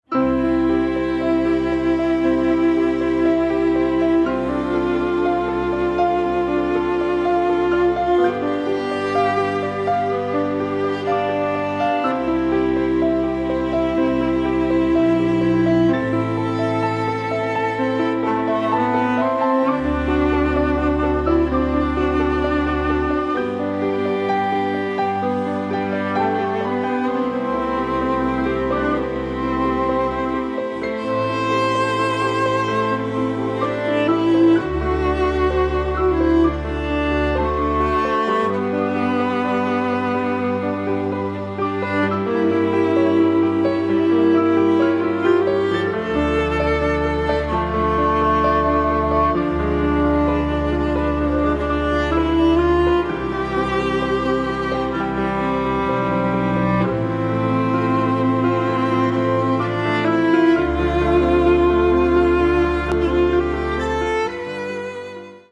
Classical arrangement